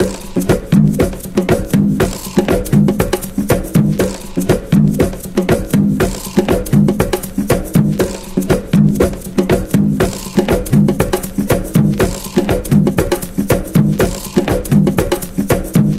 loop 3 - some african thing 1.wav